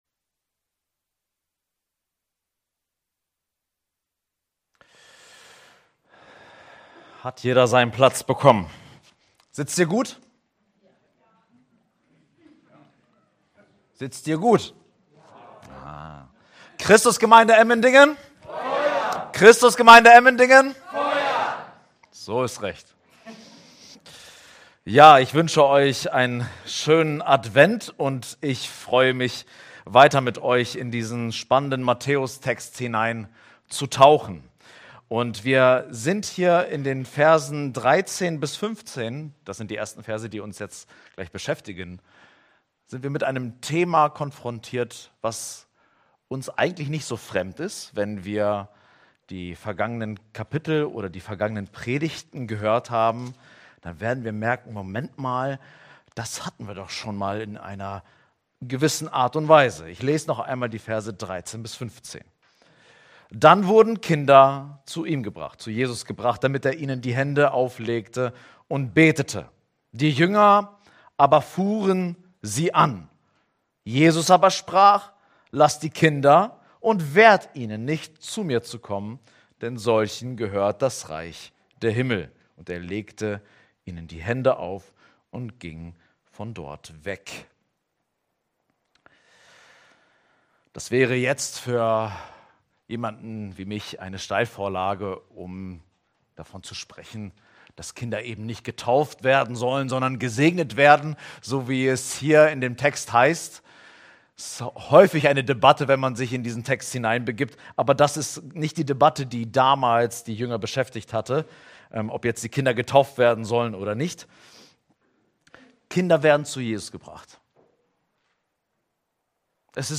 Predigt: Das unerwartete Durchhalten